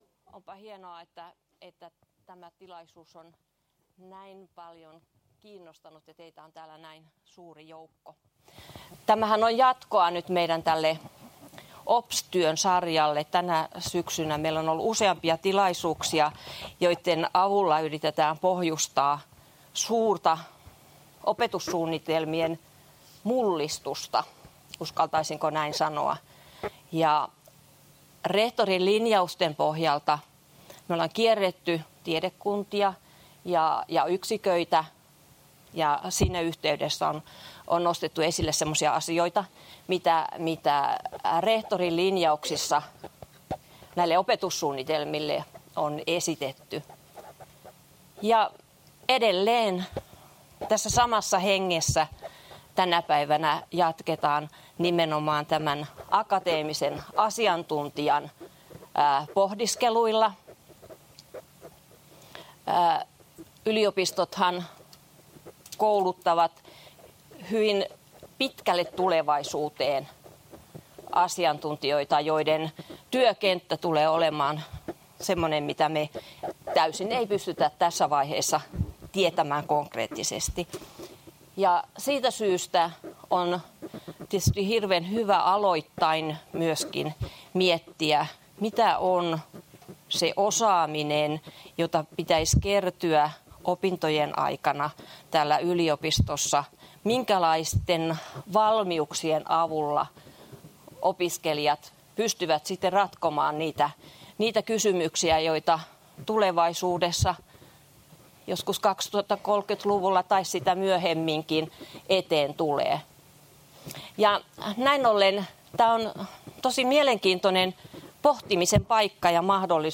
eEducation-messut 2015